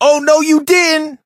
brock_hurt_05.ogg